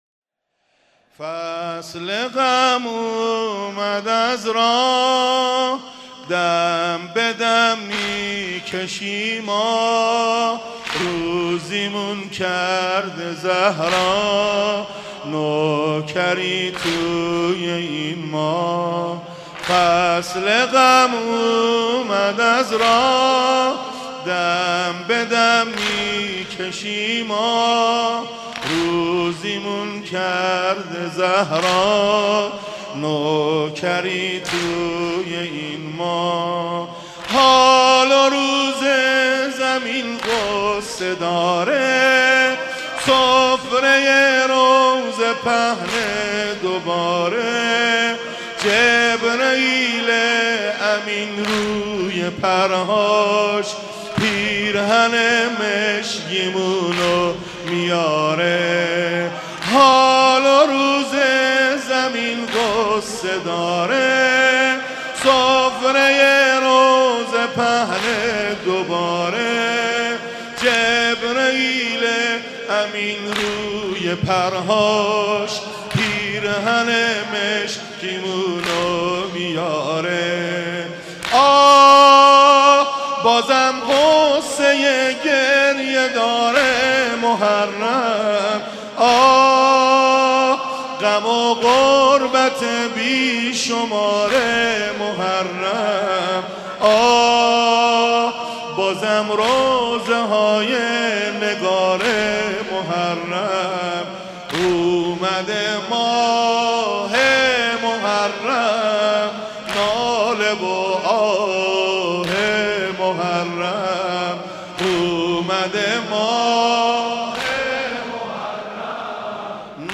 شب اول محرم الحرام 1394 | مسجد حضرت امیر
فصل غم اومد از راه | واحد | ورود به محرم